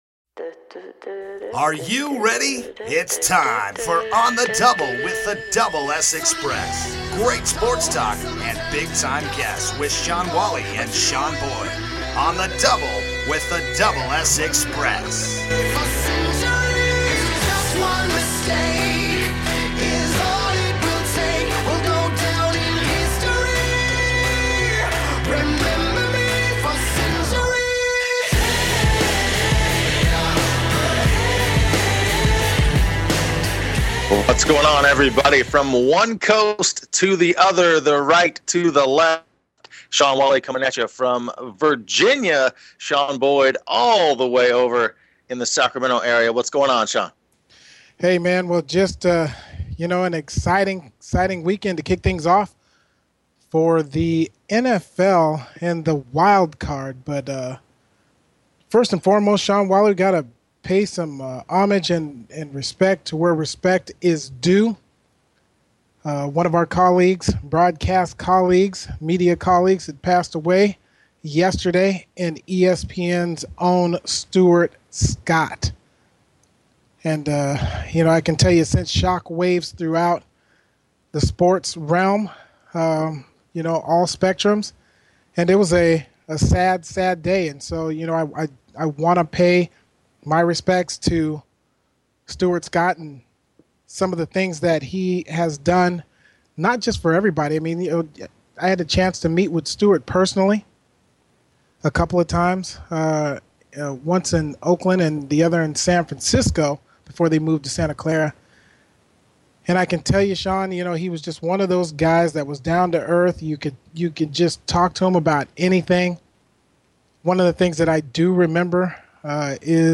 Talk Show Episode
sports talk show